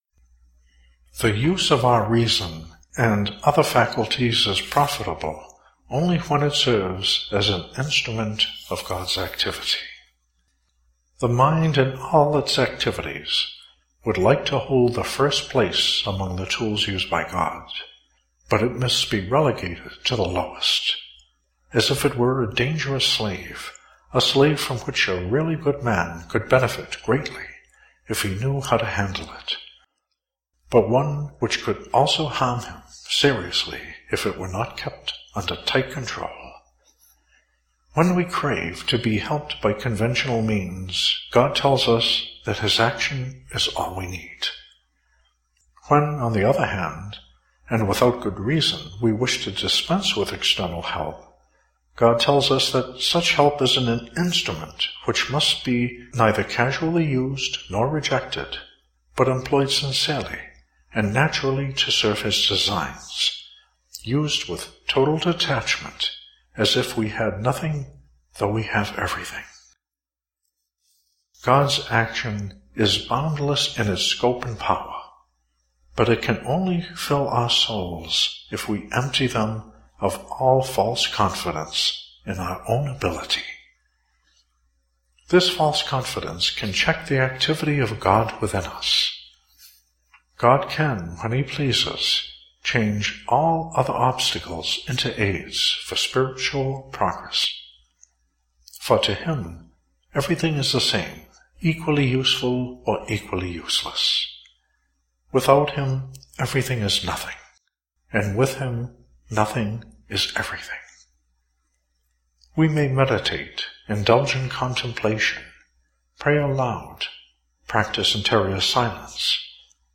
Abandonment to Divine Providence: Free Audio Book